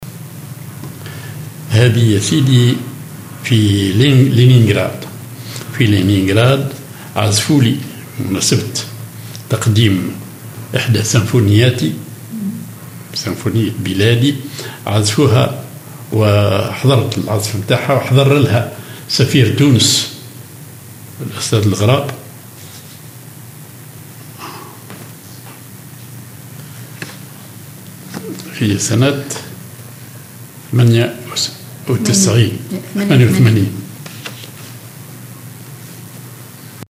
حفلة حضرها سفير تونس الأستاذ الغراب